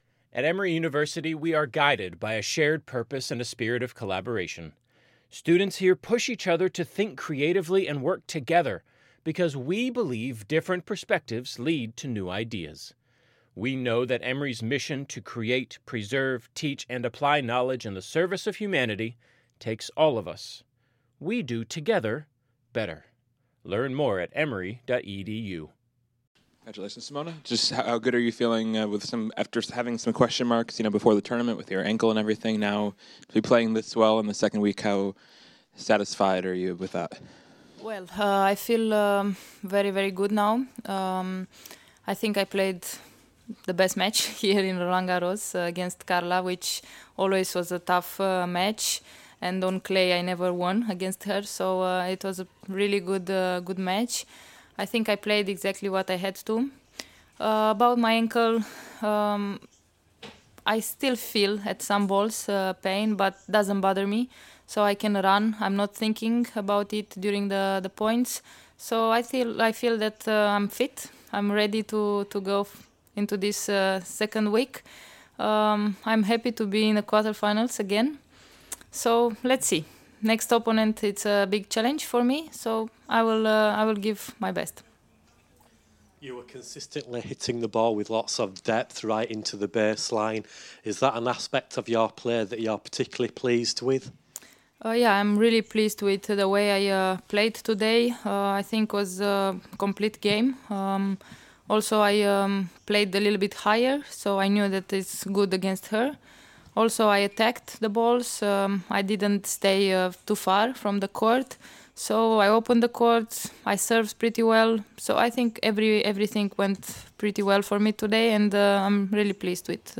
Here's Simona after her 6-1, 6-1 win vs Carla Suarez Navarro in the 4th round at Roland-Garros